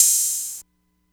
Open Hats
Metro OP Hat 3.wav